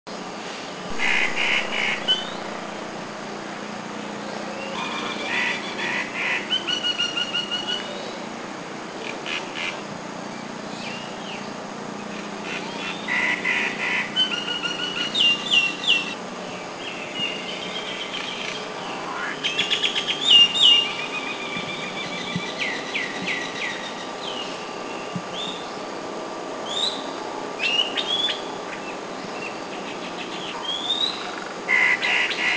Common Myna
Acridotheres tristis
Krabi
CommonMyna.mp3